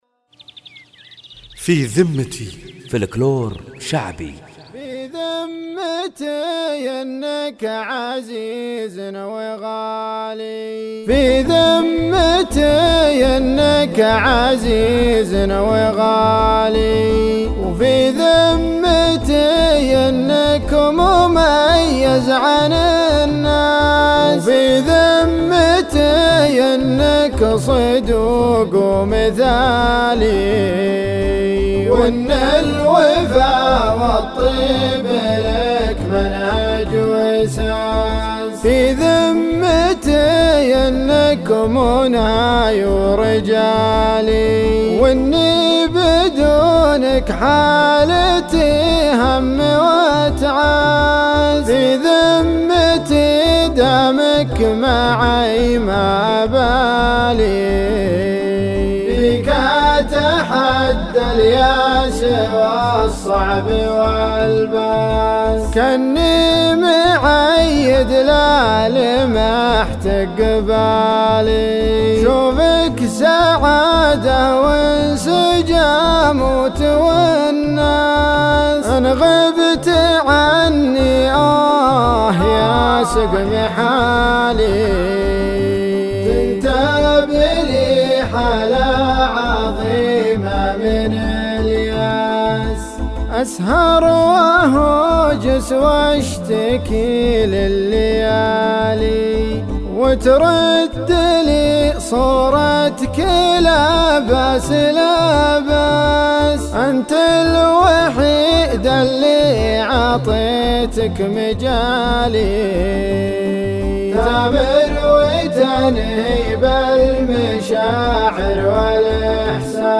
فلكلور شعبي